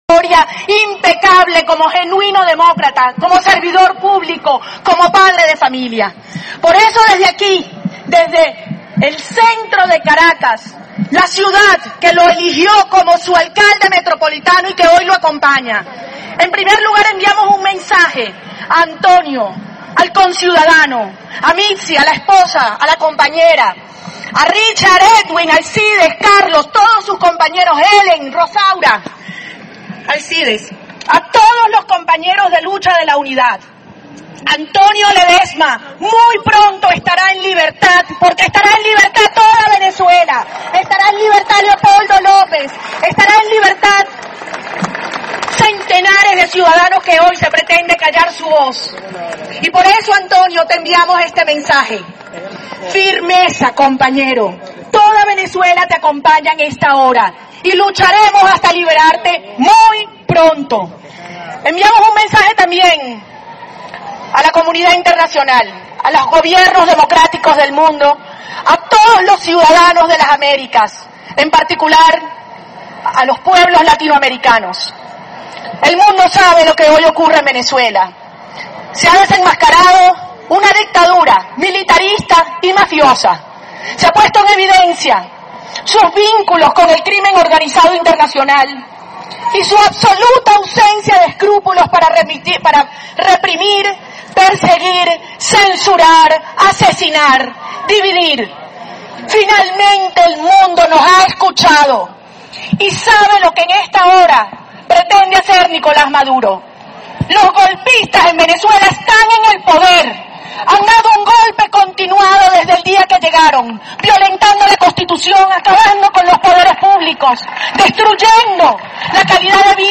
María Corina Machado durante la concentración